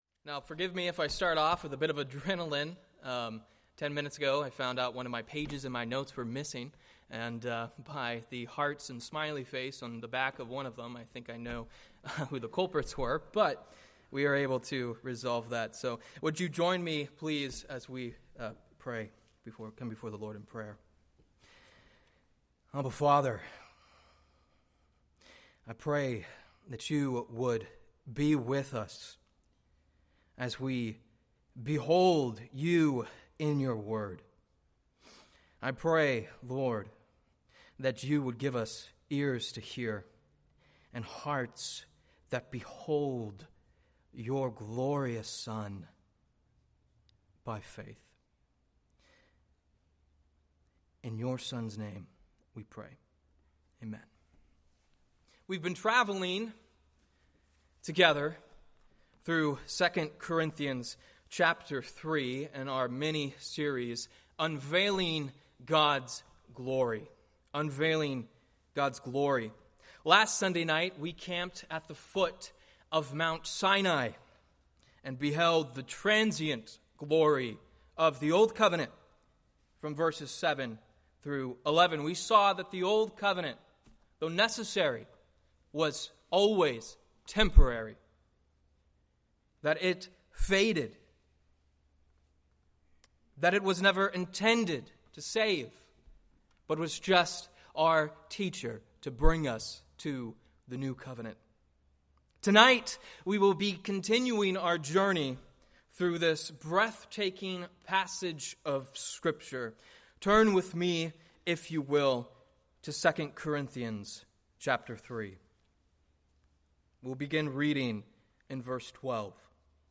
Preached May 25, 2025 from 2 Corinthians 3:12-18